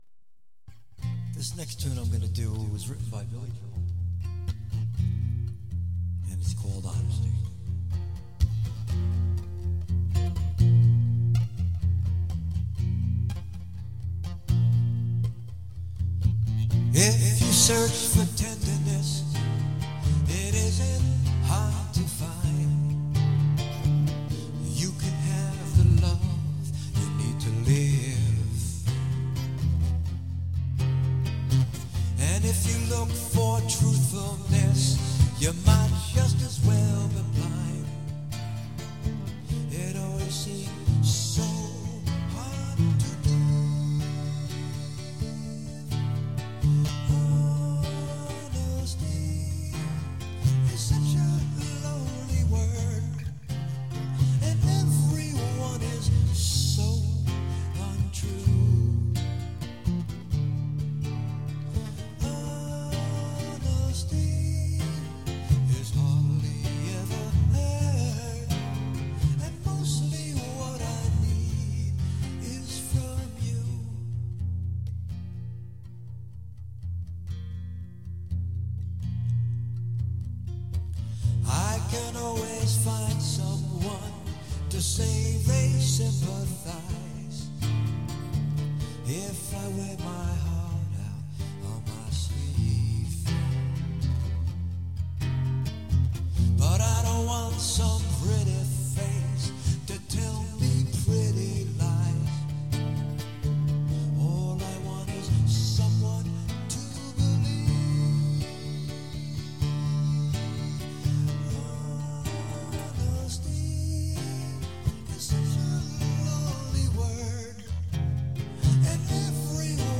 • Professional Acoustic Guitar Player
• RAW High Energy Solo Live Act
• Harmonica Player and Singer